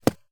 wood_punch_1.wav